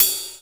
RIDE09.wav